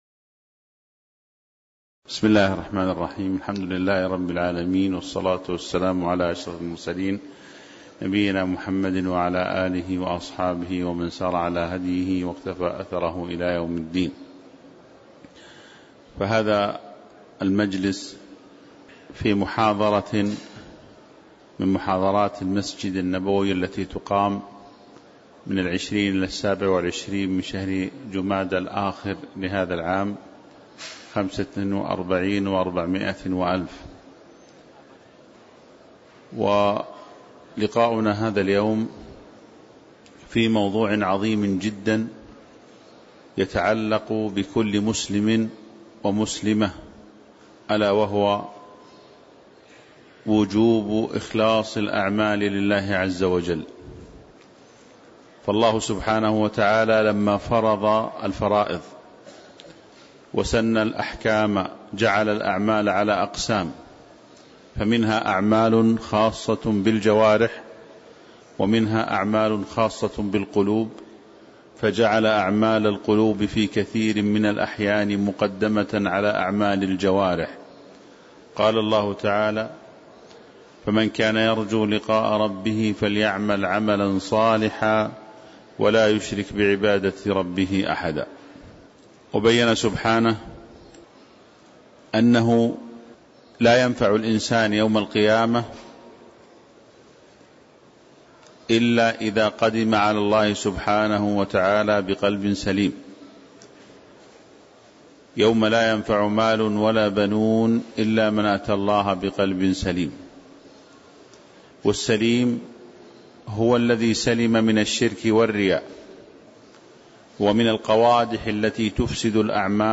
تاريخ النشر ٢٢ جمادى الآخرة ١٤٤٥ هـ المكان: المسجد النبوي الشيخ